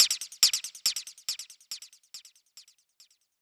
Perc (Echo).wav